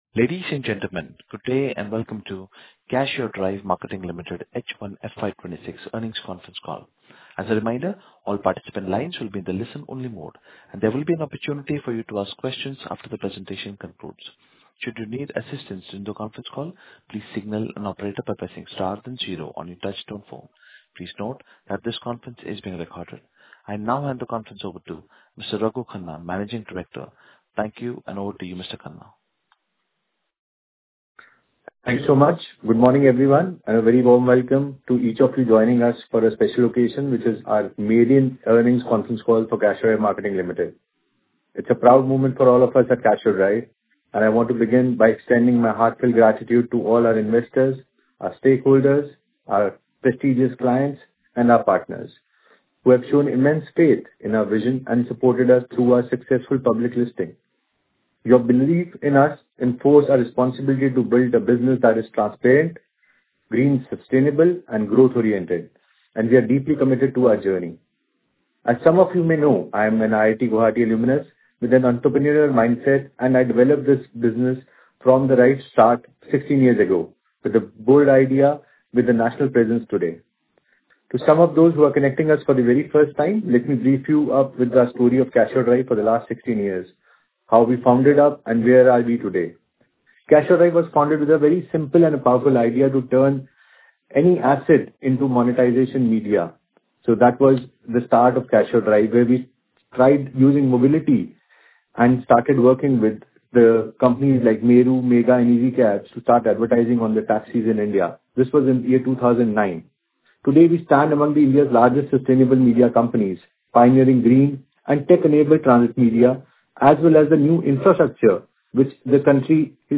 CUDML_Earnings Call Recording.mp3